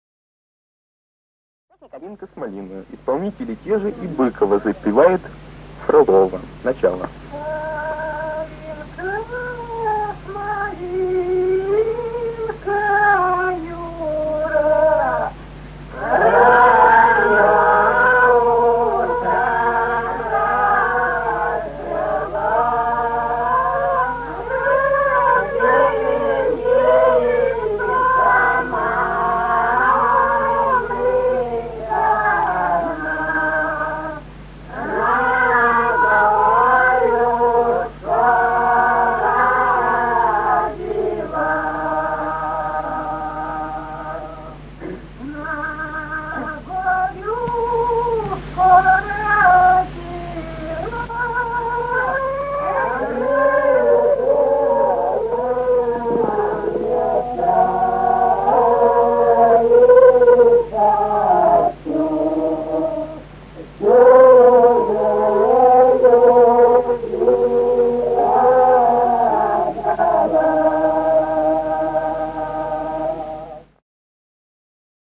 Русские народные песни Владимирской области 30. Калинка с малинкою (лирическая) с. Михали Суздальского района Владимирской области.